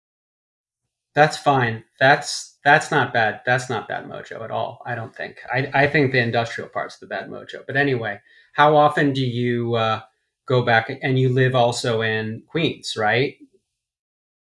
Fixing laptop-mic audio
My ask is simply what tools you would recommend to minimize the canned sound and get it closer to what it should have been via the external mic- what suggested EQ and compression chains would you recommend for the attached clip?